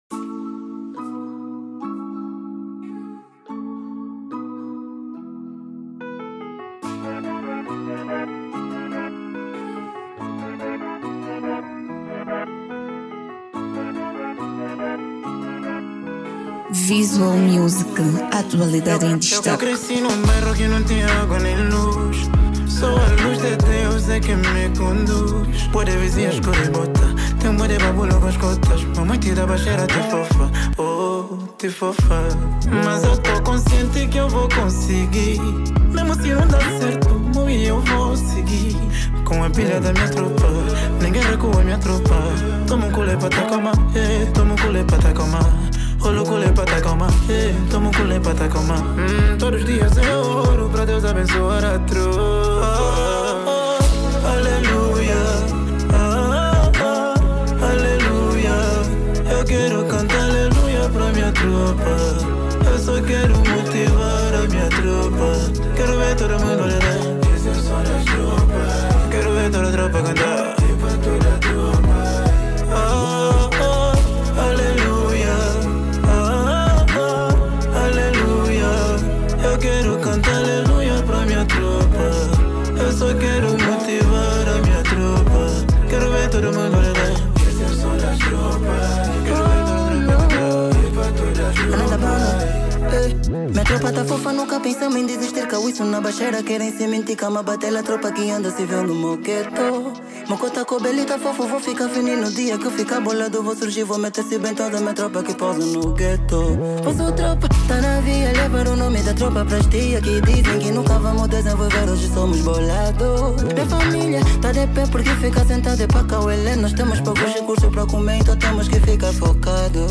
Género: Rumba